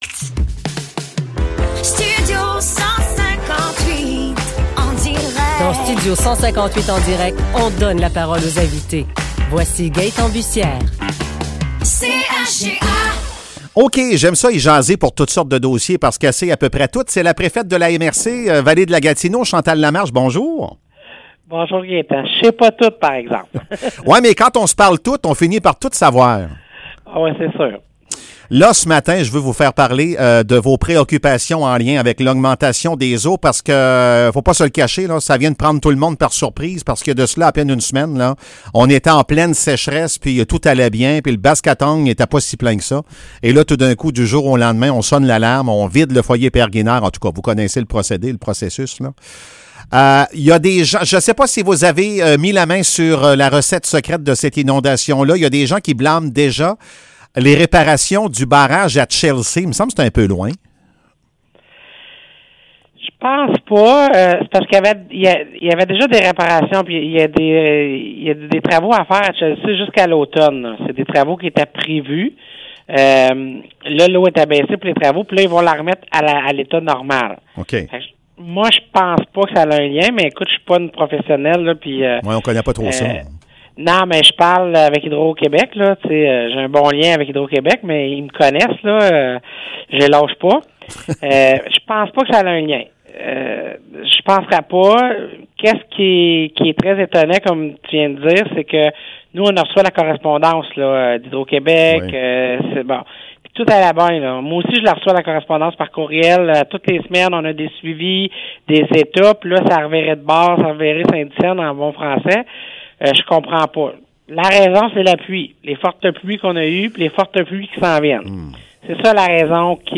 Entrevue avec Chantal Lamarche, préfète de la MRC Vallée-de-la-Gatineau